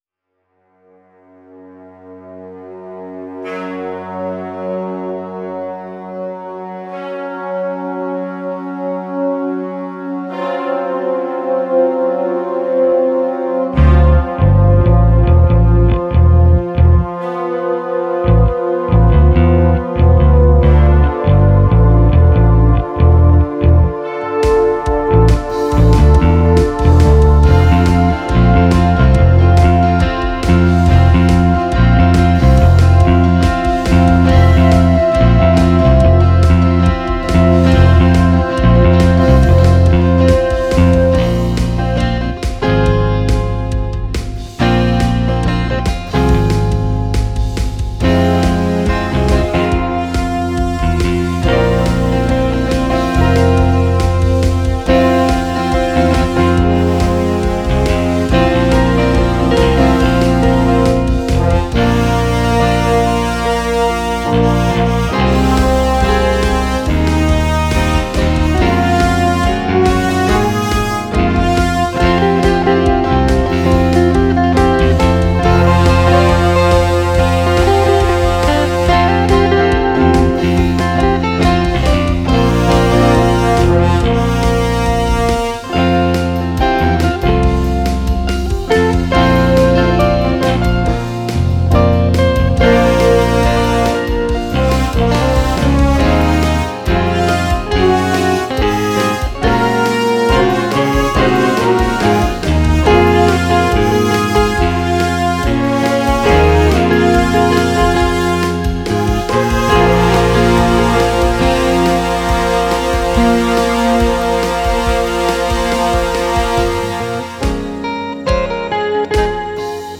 Alle Stücke auf dieser Seite liegen in CD-Qualität vor.